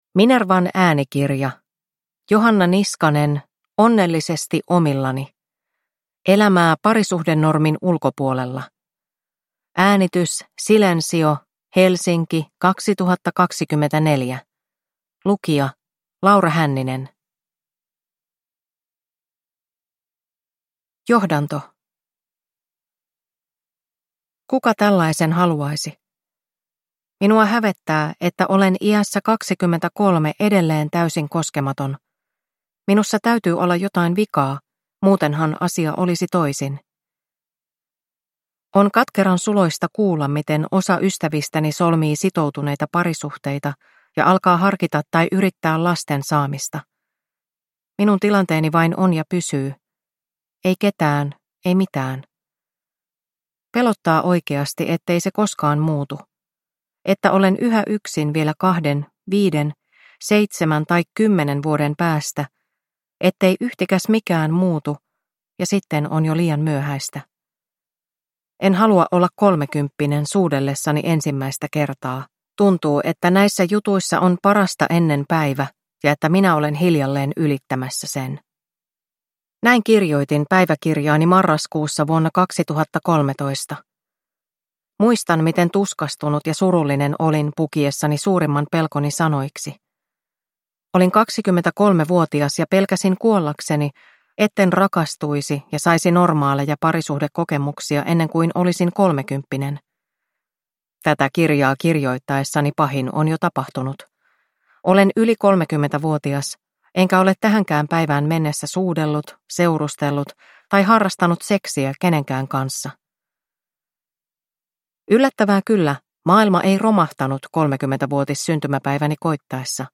Onnellisesti omillani – Ljudbok